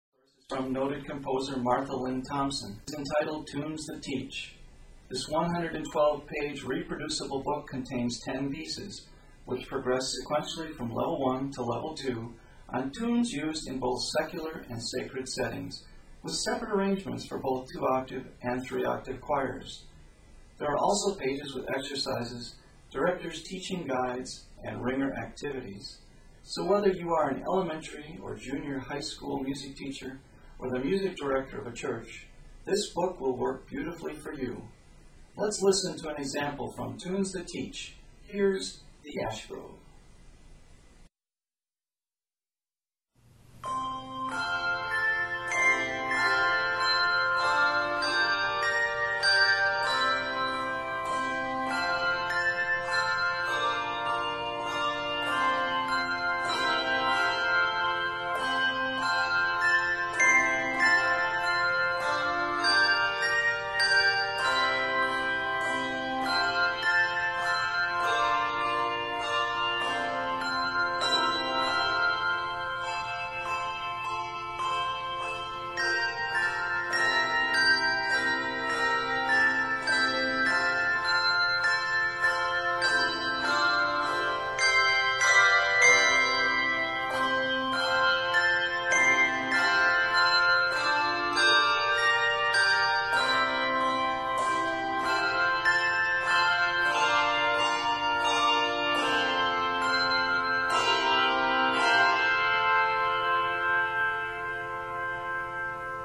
handbell music